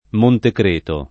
Montecreto [ montekr % to ]